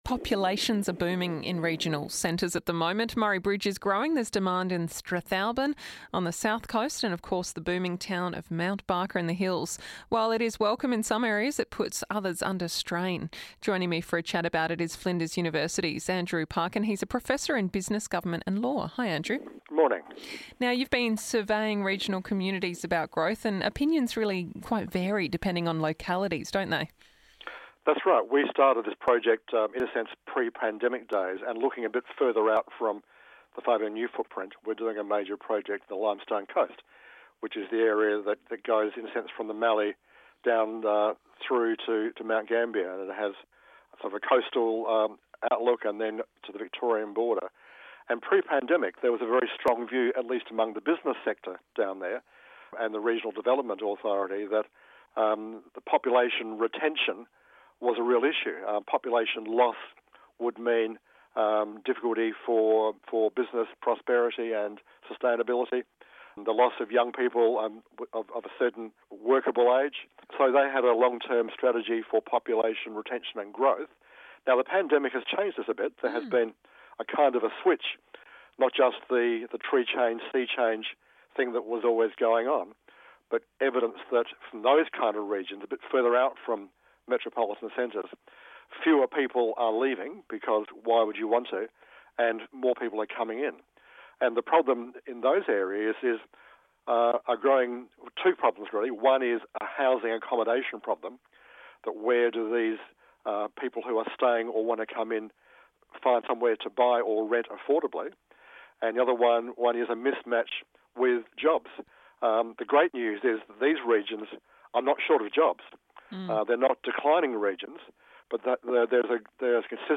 a chat about it.